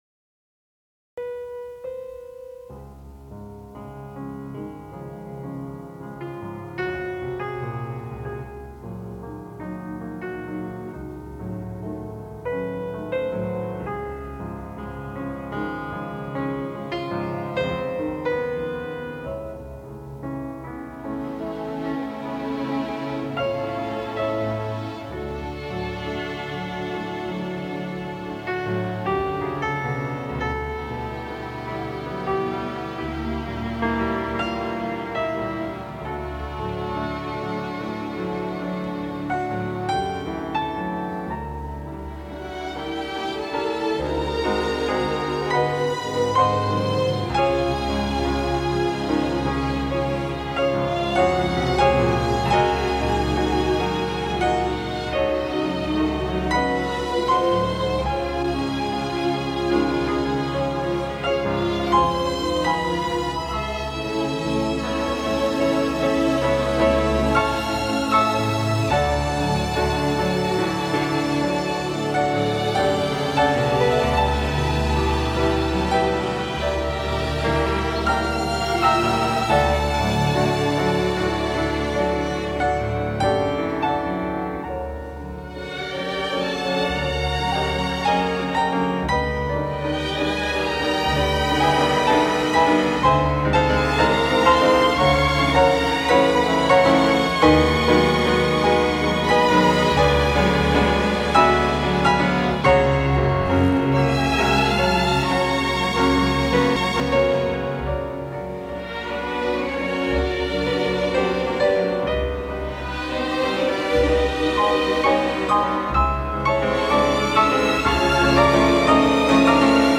Instrumentation: Piano
Ensemble: Solo
Instrument / Voice: Piano